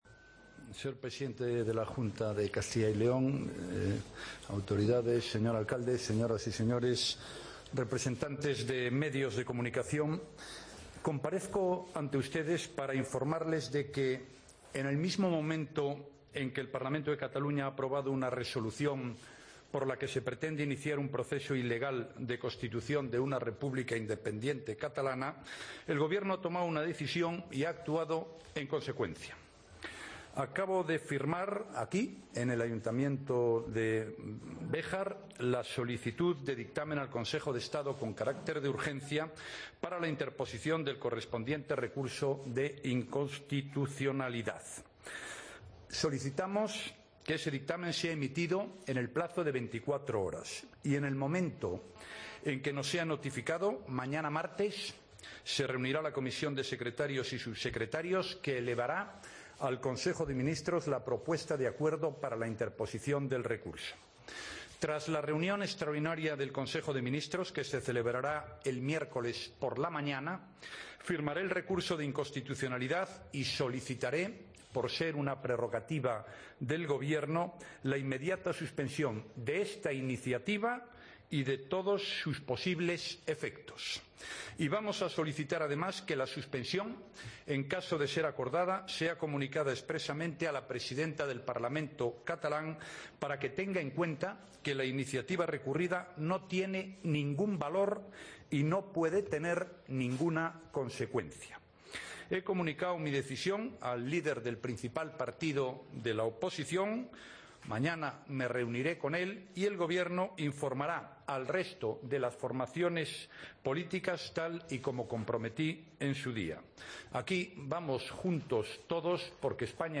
Escucha la declaración institucional de Mariano Rajoy sobre la resolución de independencia aprobada en el Parlament
Redacción digital Madrid - Publicado el 09 nov 2015, 13:20 - Actualizado 19 mar 2023, 03:33 1 min lectura Descargar Facebook Twitter Whatsapp Telegram Enviar por email Copiar enlace Desde el Ayuntamiento de Béjar, Salamanca.